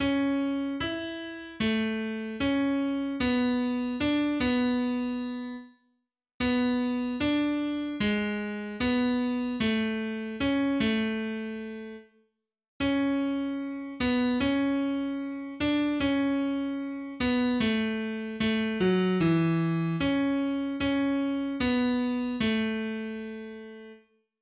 311   "Fast Train {Bass}" (A-Dur, eigene) .pdf .capx .mid